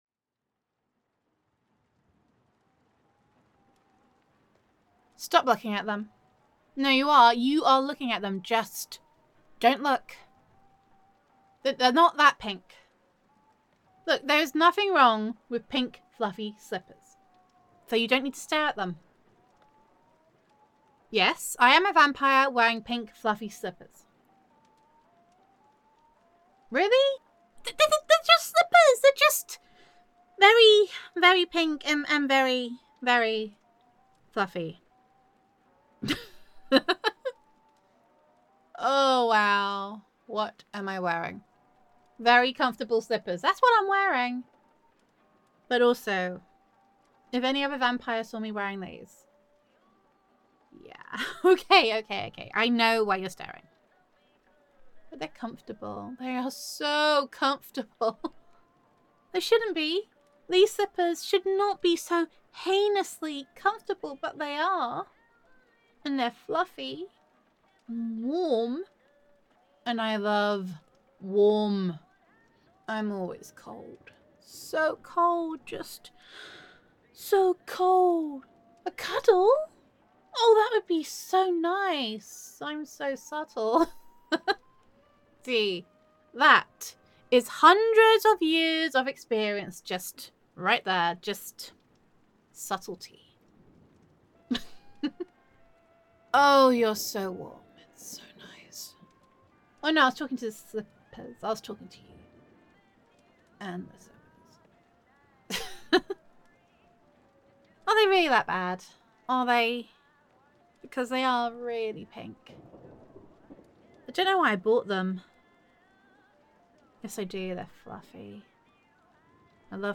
[F4A]
[Vampire Roleplay][Girlfriend Roleplay]